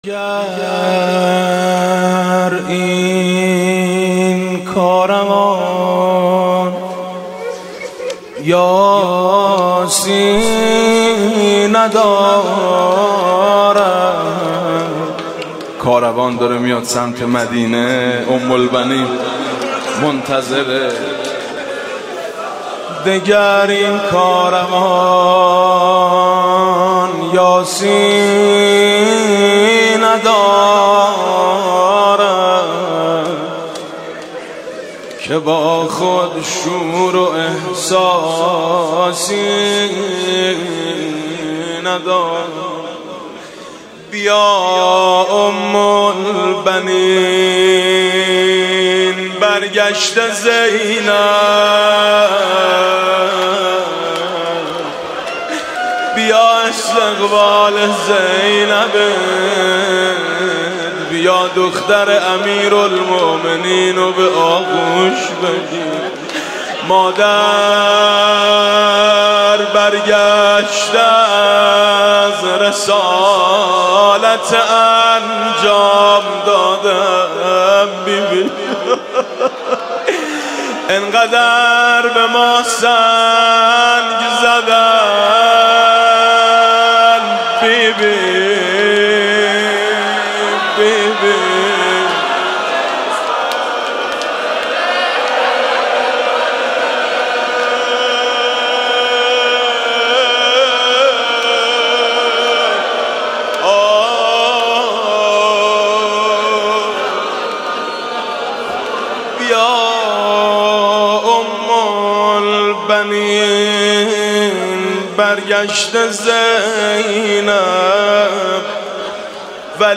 مناسبت : وفات حضرت ام‌البنین سلام‌الله‌علیها
مداح : میثم مطیعی قالب : روضه